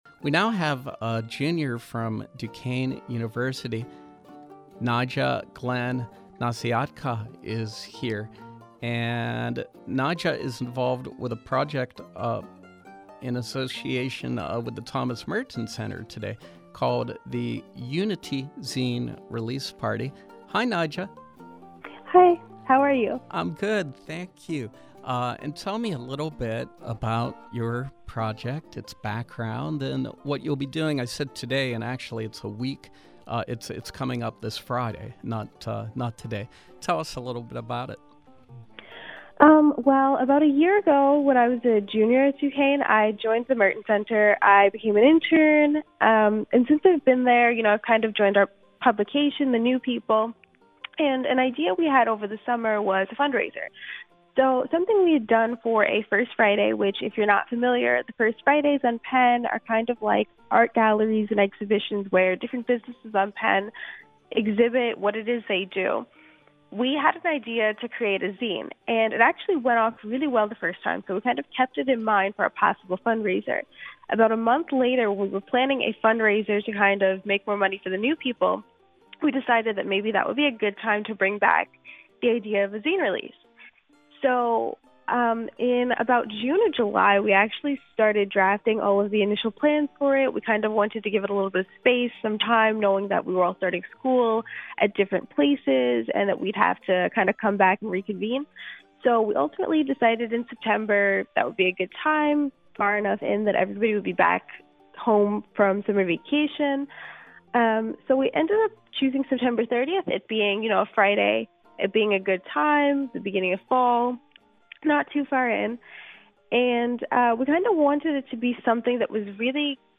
Interview: [You]nity Zine Release Party